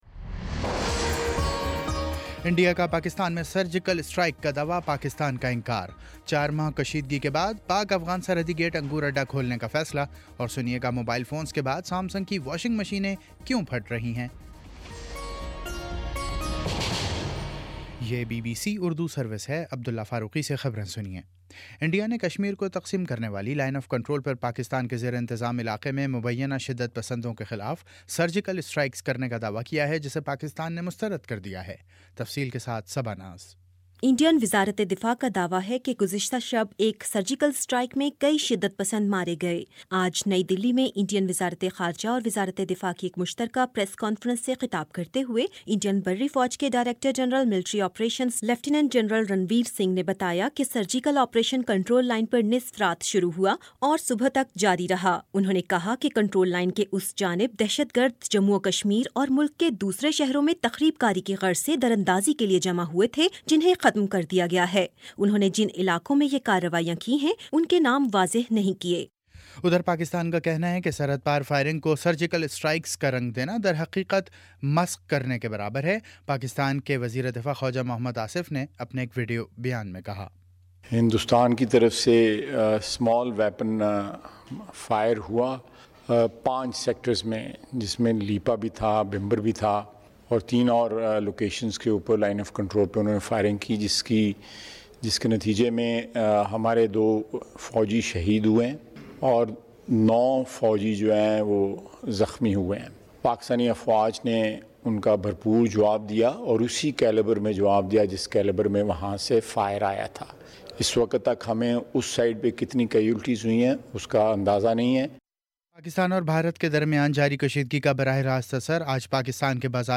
ستمبر29 : شام چھ بجے کا نیوز بُلیٹن